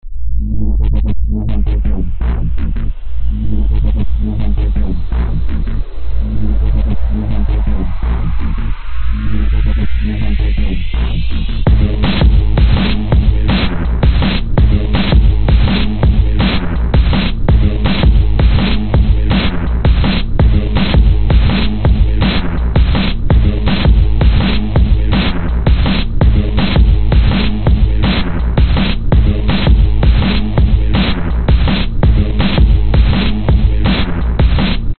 INSAIN Bass 3
标签： 165 bpm Drum And Bass Loops Bass Loops 1 002.37 KB wav Key : Unknown
声道立体声